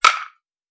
Bat Hit Wood Powerful.wav